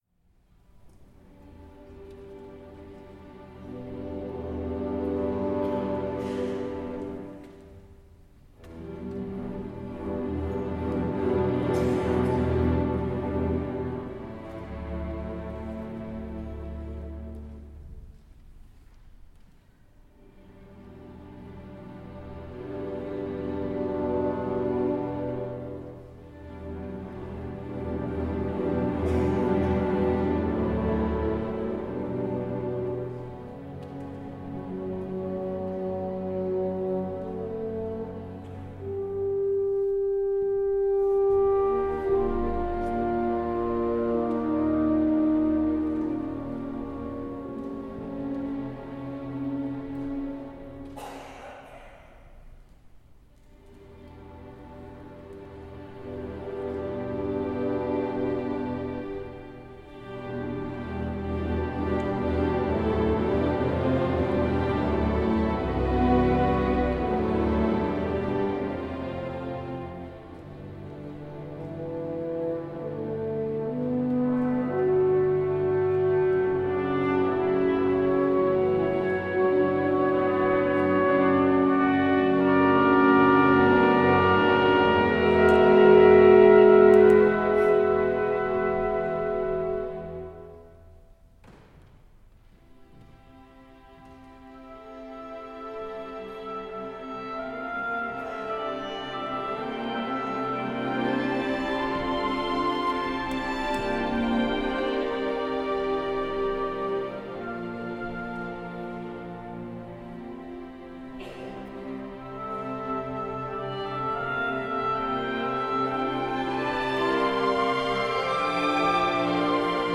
Instrumentation:2.2.2.2 / 2hn.2tp T+1 strings